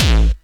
VEC3 Bassdrums Dirty 29.wav